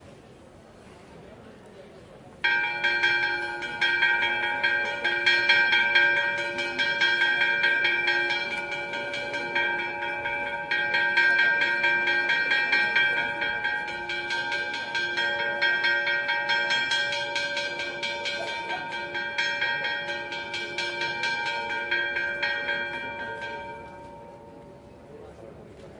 描述：在Baiona（Pontevedra）的Madres Dominicas修道院录制适合或火的铃声。2015年8月15日，18：47：55.MS侧微观水平：角度幅度为90度。
Tag: 钟声 Madres-Dominicas 振铃换火 巴约纳 振铃换配合 Val- Minhor 修道院 现场记录